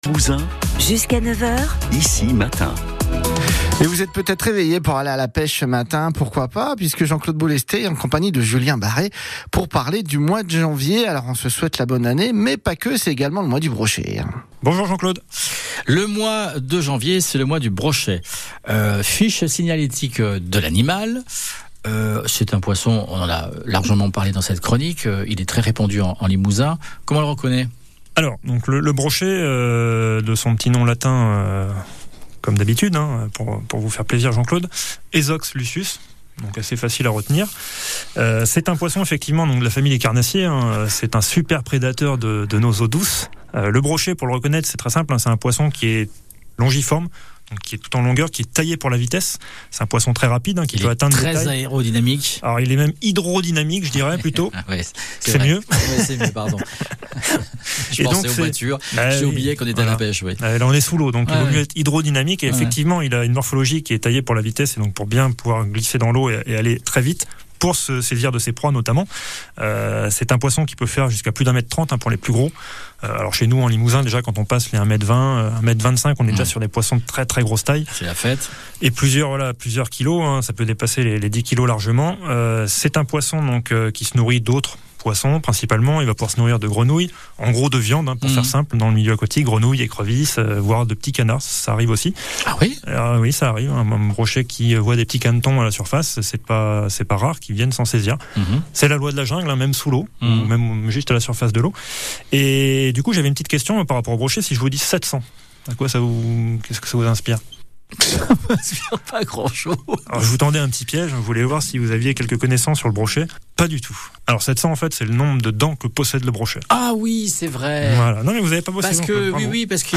[📻 ON AIR]